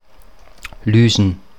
Standarddeutsche Form
[ˈlyːzn]
Lüsen_Standard.mp3